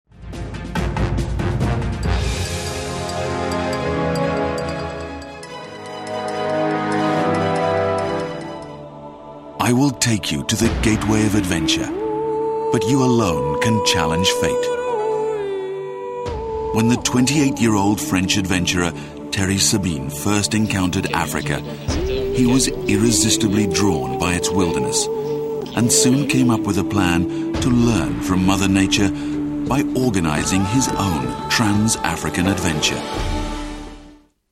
イギリス英語 男性